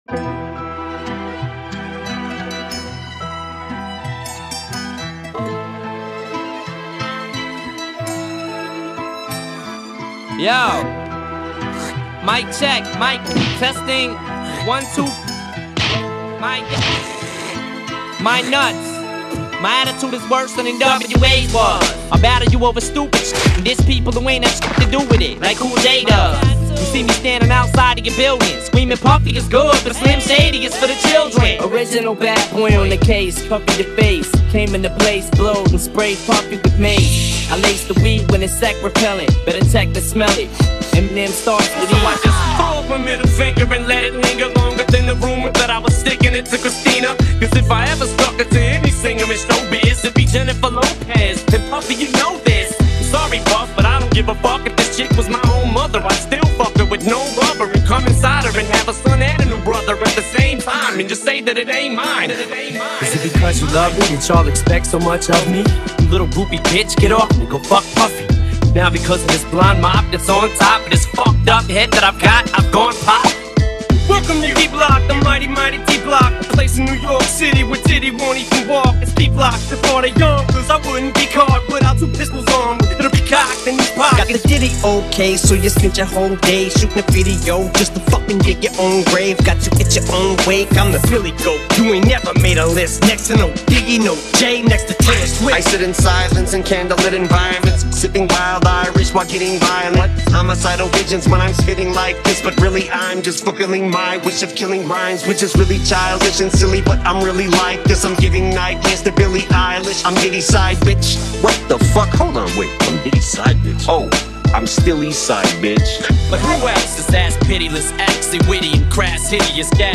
Репчик от знаменитого
Американского певца